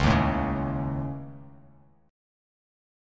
Dbsus2#5 chord